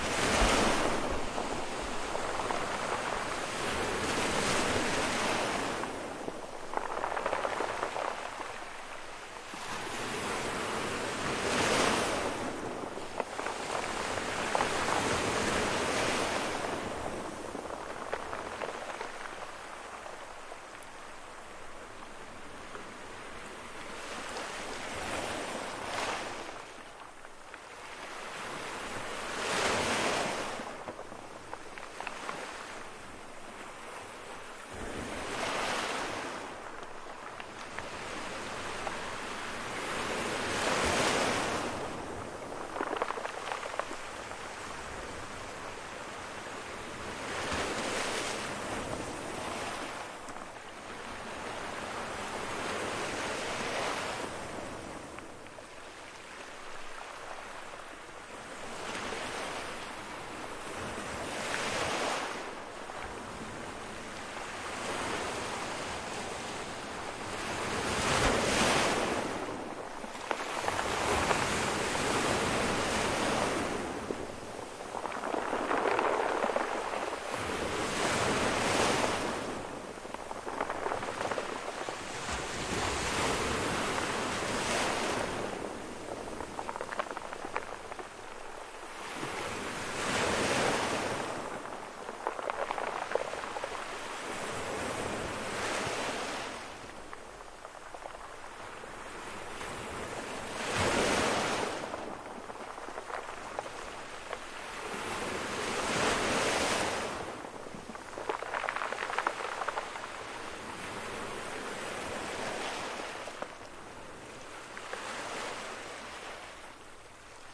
Cuba » waves beach medium3 close
描述：waves beach medium close
标签： waves beach medium close
声道立体声